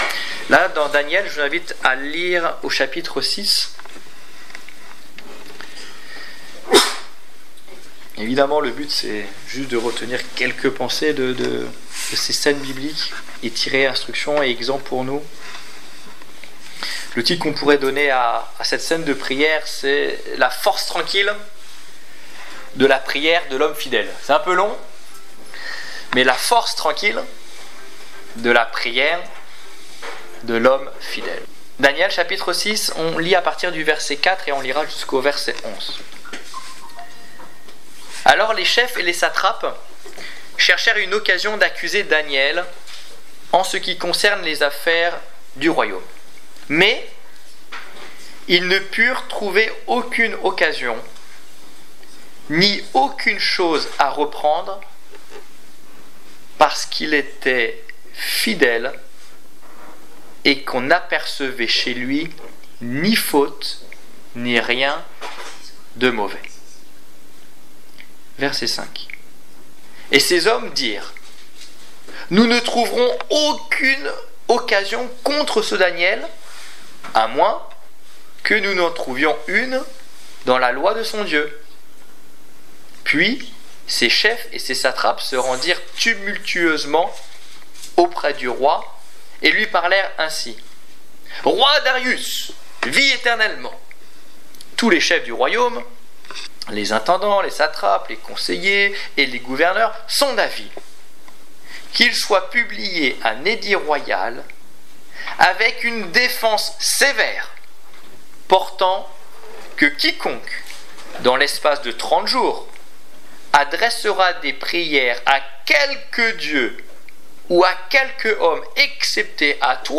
Étude biblique du 13 janvier 2016